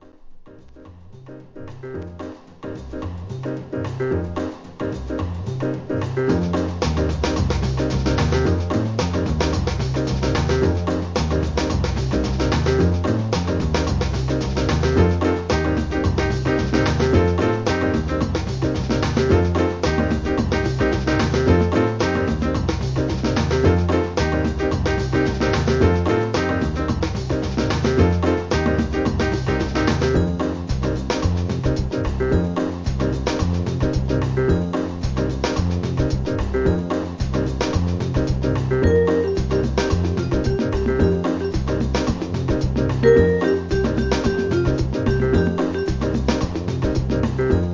ラテン〜JAZZな洒落オツ・サウンド!!